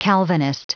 Prononciation du mot calvinist en anglais (fichier audio)
Prononciation du mot : calvinist